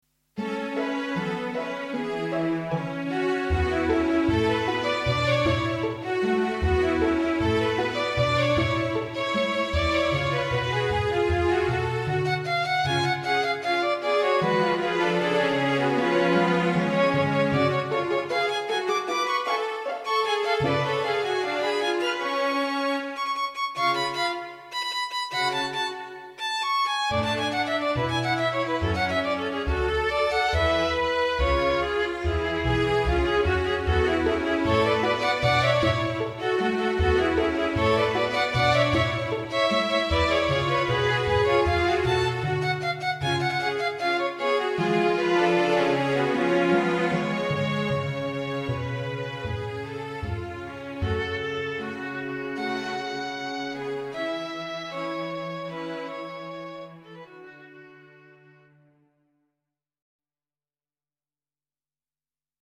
klassiek
Intermezzo tijdens een opera